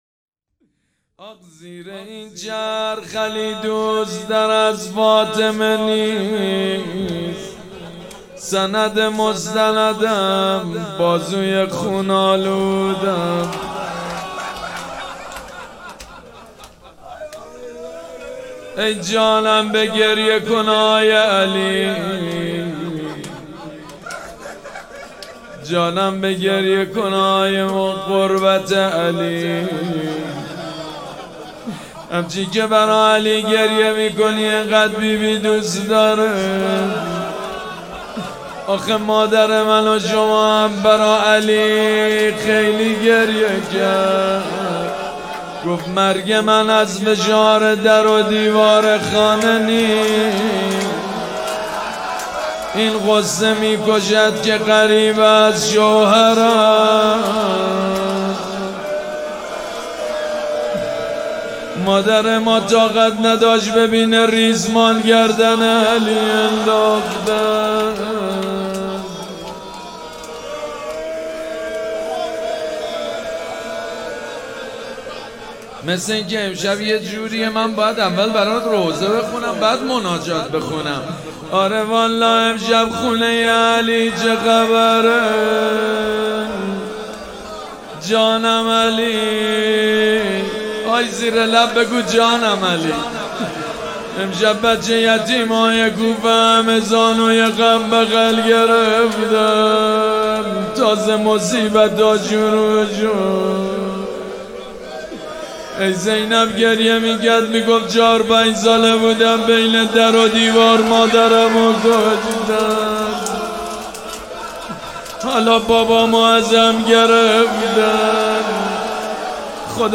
حسینیه ریحانة‌الحسین (سلام‌الله‌علیها)
روضه
مداح